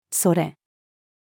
それ-female.mp3